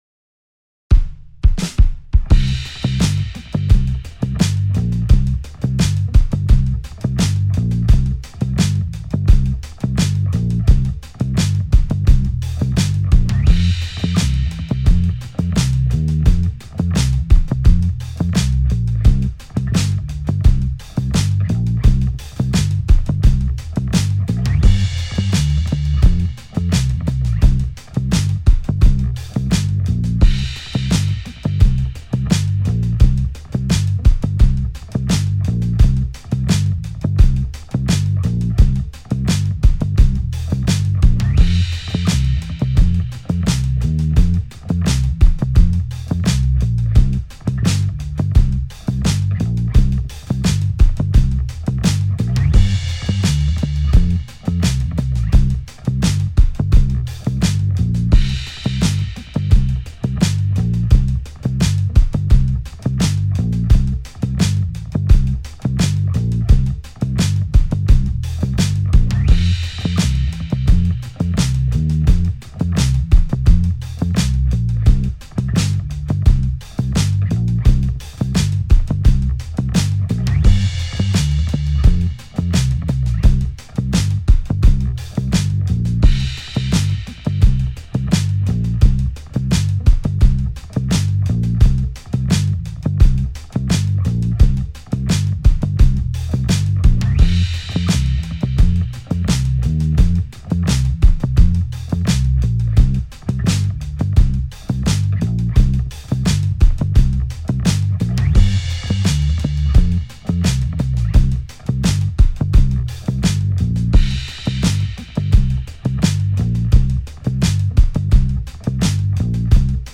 Acompañamiento-Cm.mp3